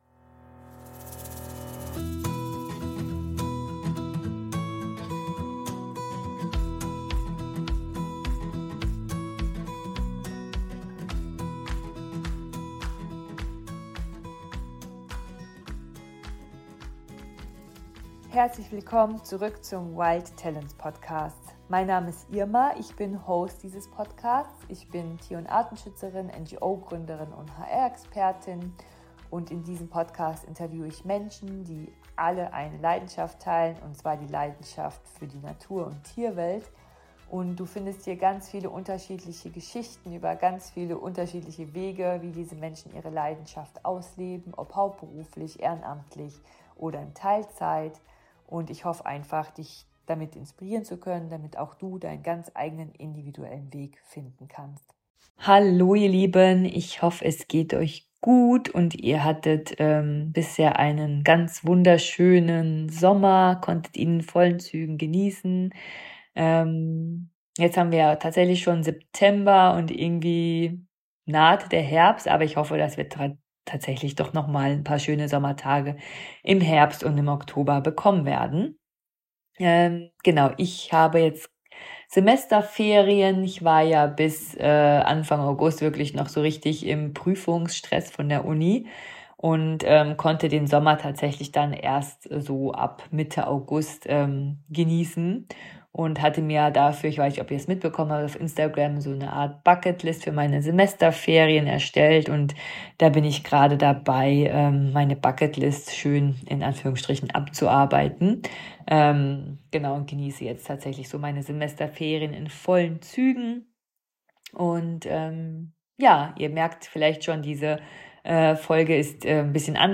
Beschreibung vor 7 Monaten Willkommen zurück bei WILD TALENTS: Tierische Karrieren im Rampenlicht Zwei Semester, viele Learnings: Vom Chaos im ersten Semester bis zum Flow im zweiten. In dieser Solofolge gebe ich dir einen ehrlichen Rückblick auf meinen Studienstart in Ökologie, Geographie & Naturschutz - mit allen Höhen, Tiefen und einem kleinen Magic Moment.